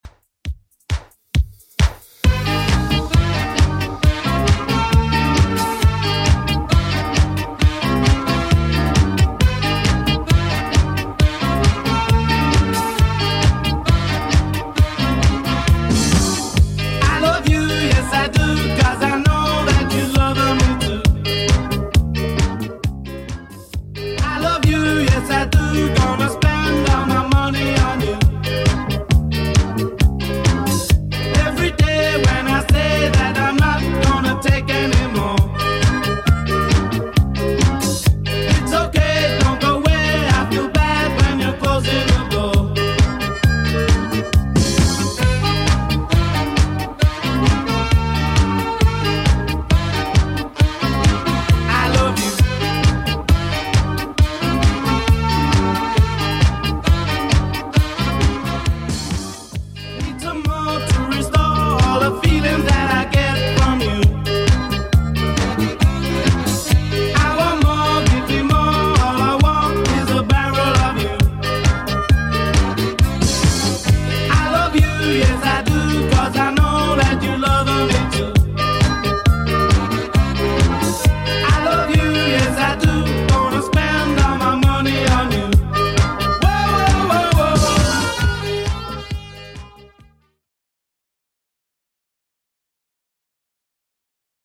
Genre: 80's
BPM: 114